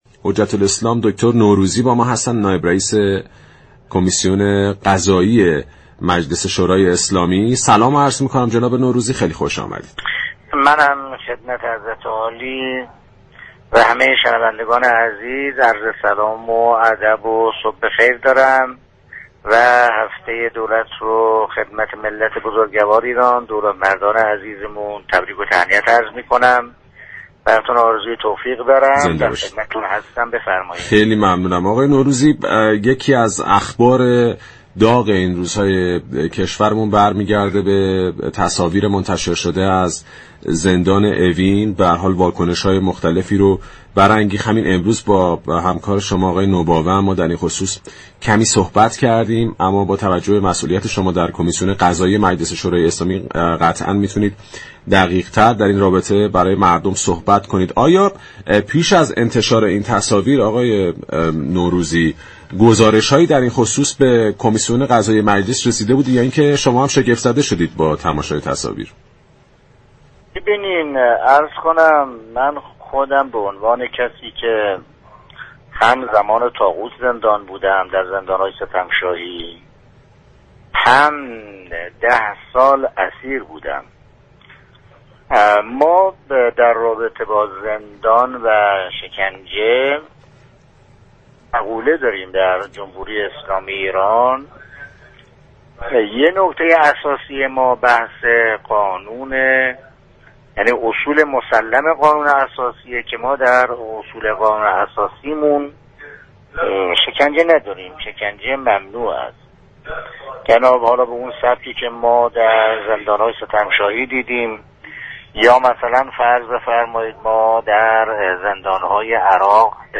به گزارش پایگاه اطلاع رسانی رادیو تهران، حجت الاسلام دكتر حسن نوروزی نایب رئیس كمیسیون قضایی مجلس شورای اسلامی در گفتگو با پارك شهر رادیو تهران، در خصوص تصاویر منتشر شده از زندان اوین گفت: در اصول قانون اساسی كشورمان شكنجه نداریم و ممنوع است.